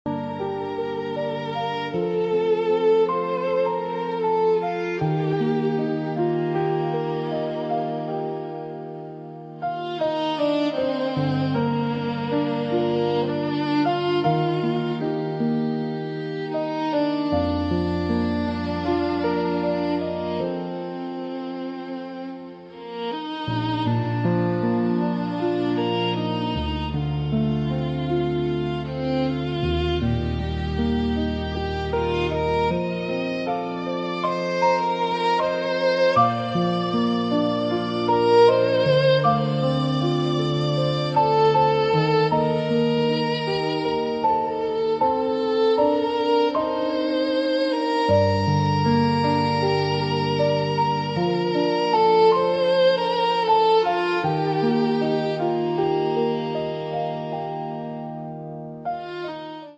calming piano
soft piano music